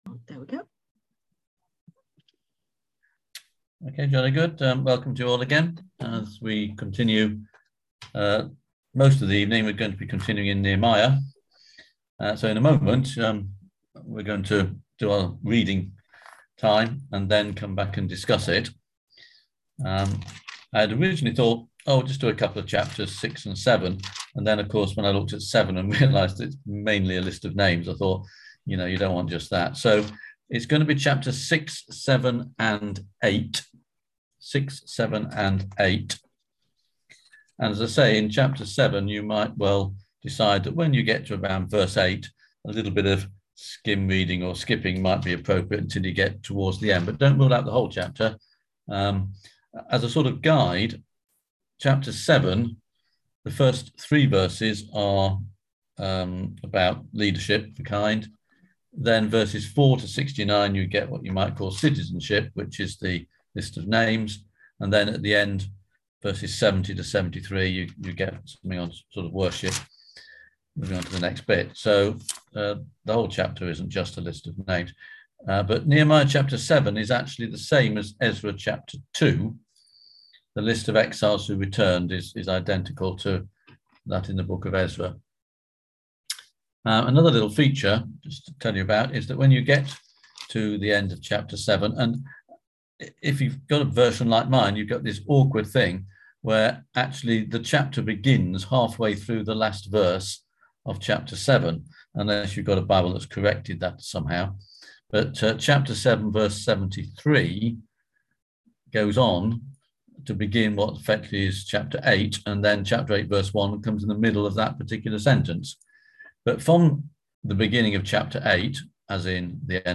Bible School
On November 18th at 7pm – 8:30pm on ZOOM